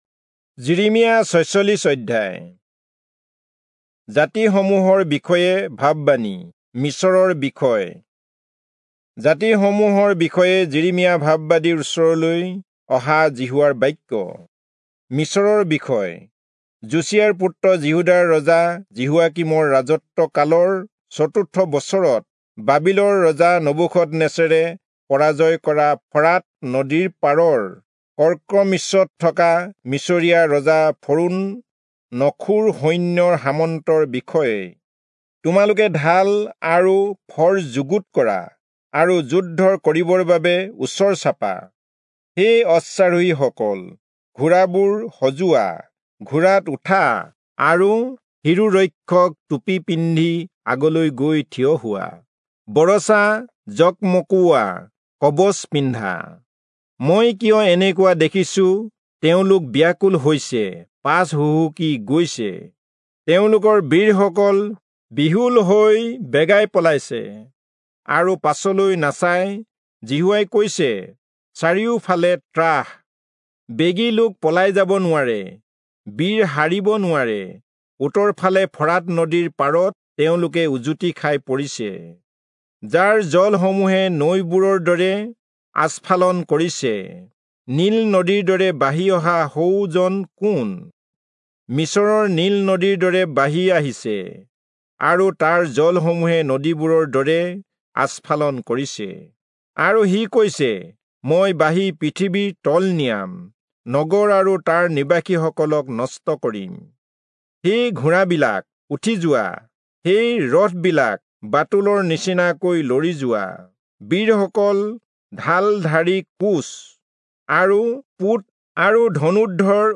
Assamese Audio Bible - Jeremiah 46 in Tov bible version